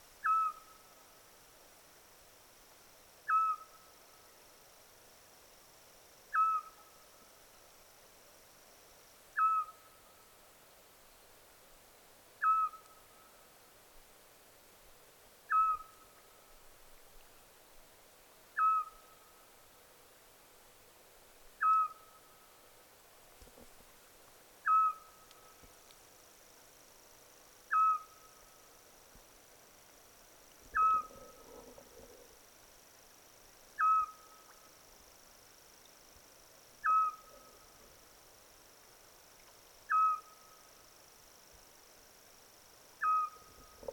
L’ASSIOLO (Otus scops)
L’assiolo anima le notti d’estate con il suo richiamo caratteristico, breve, ripetitivo al quale si deve il suo “soprannome” chiù. L’attività di canto comincia poco dopo il tramonto e finisce all’alba spesso con uno scambio di richiami tra femmina e maschio.